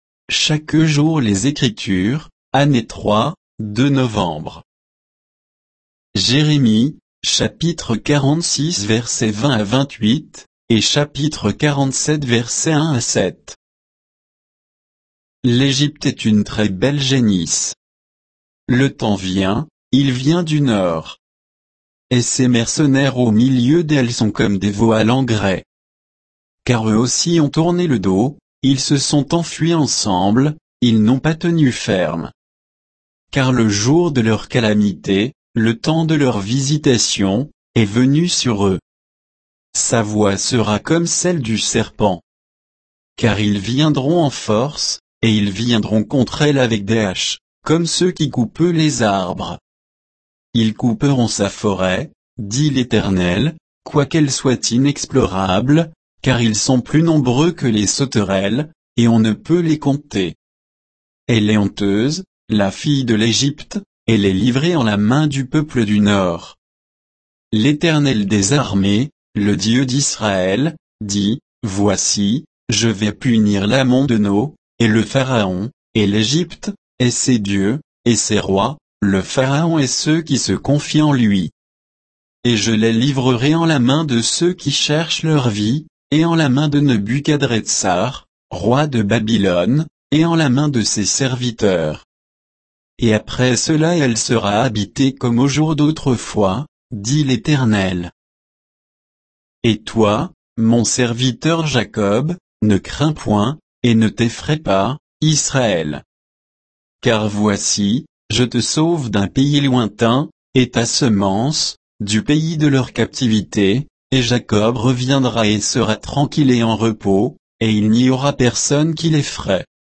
Méditation quoditienne de Chaque jour les Écritures sur Jérémie 46